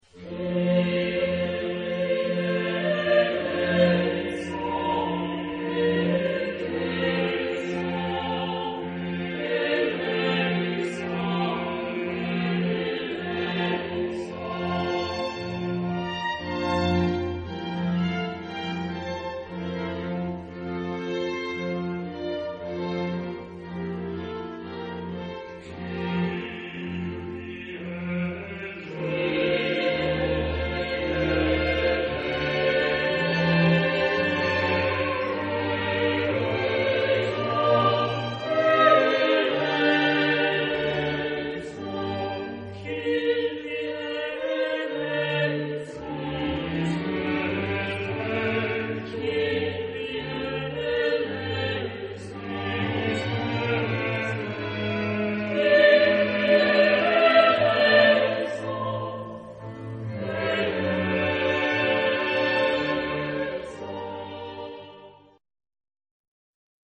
Genre-Style-Form: Sacred ; Classic
Mood of the piece: larghetto
Type of Choir: SATB  (4 mixed voices )
Instrumentation: Strings + continuo  (4 instrumental part(s))
Instruments: Violin I ; Violin II ; Viola (1) ; Basso continuo
Tonality: F major